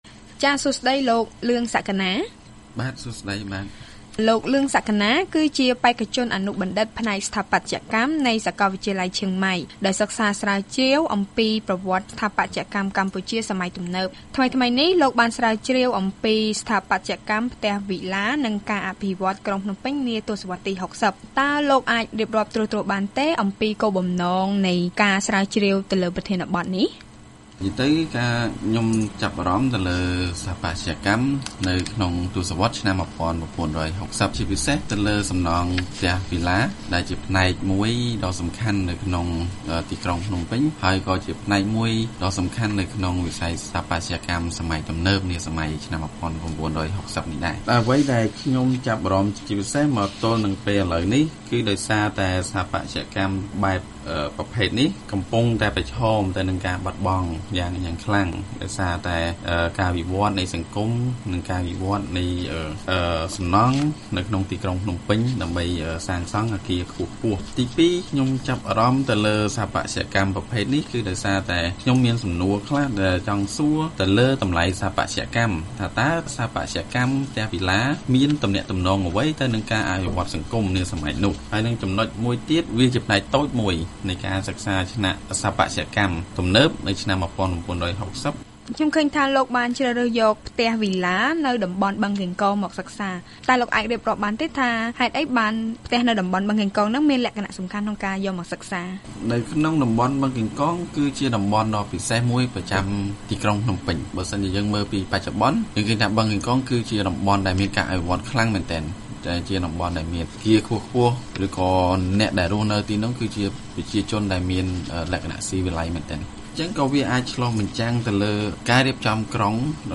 បទសម្ភាសន៍ VOA៖ និស្សិតស្រាវជ្រាវពីសំណង់ទសវត្សរ៍ទី៦០ដែលប្រឈមនឹងការបាត់បង់